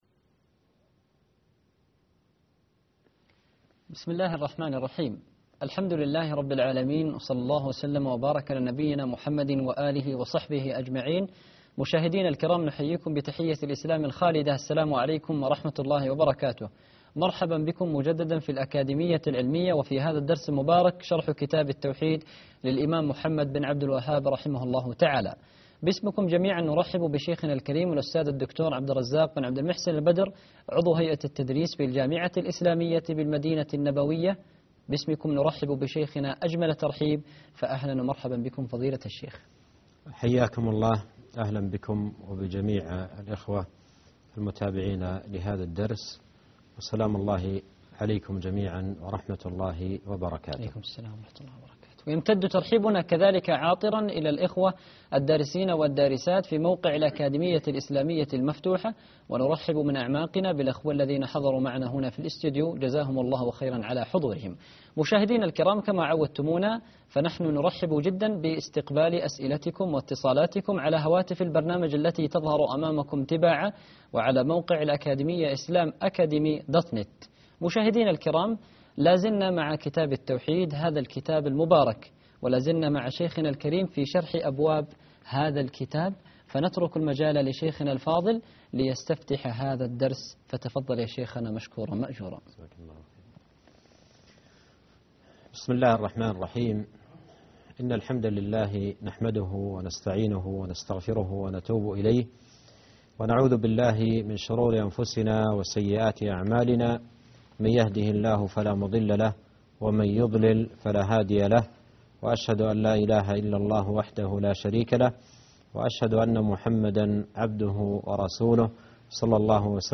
الدرس 22 _ العبادة حق لله تعالى